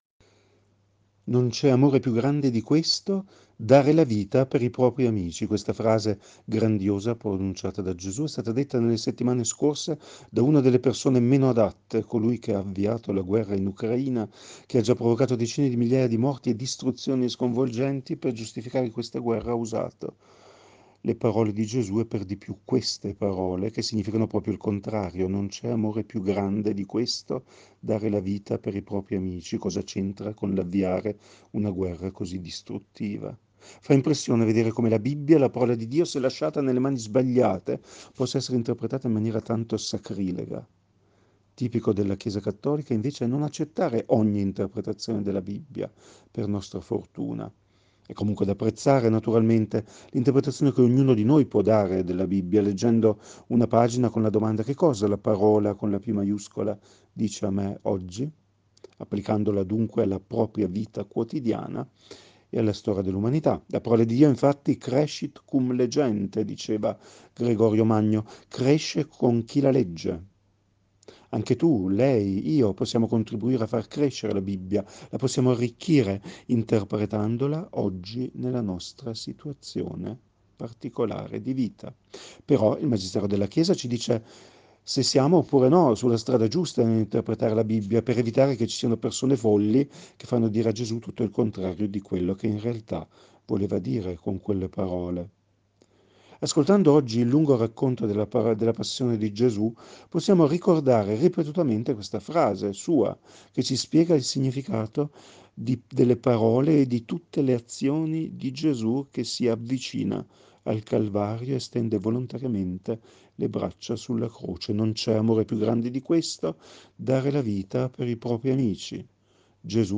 Meditazione Domenica delle Palme 10 Aprile 2022